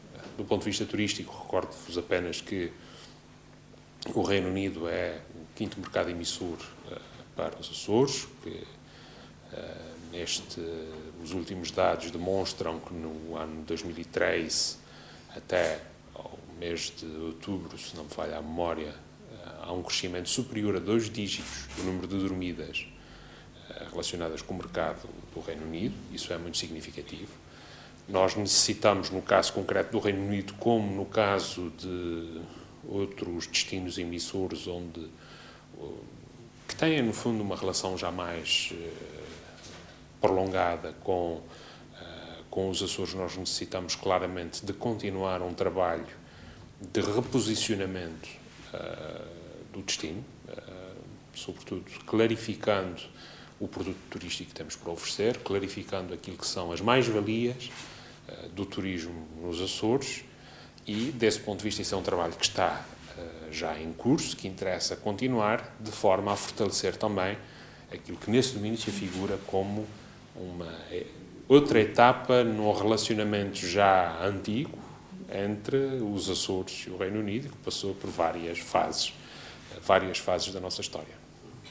“Desse ponto de vista, este é um trabalho que já está em curso e que interessa continuar, de forma a fortalecer também aquilo que se afigura como outra etapa no relacionamento já antigo entre os Açores e o Reino Unido, que passou por várias fases da nossa história”, salientou Vasco Cordeiro, em declarações aos jornalistas.